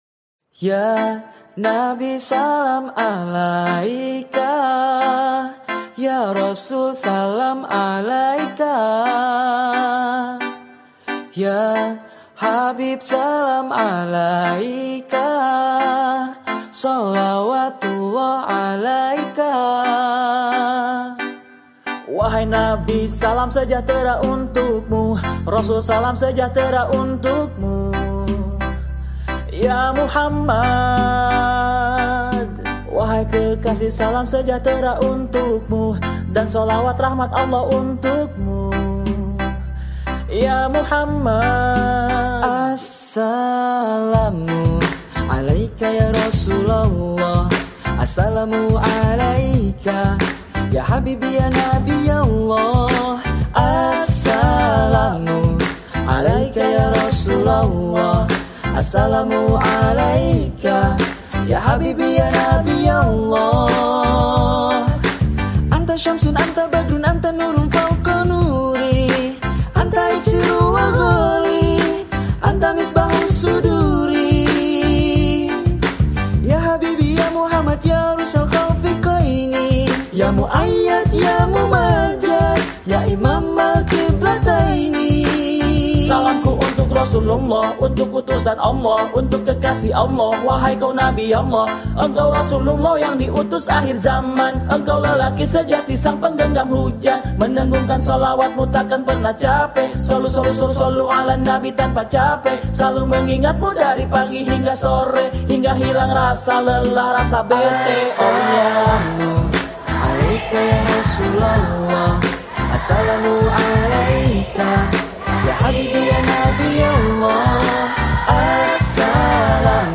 Religi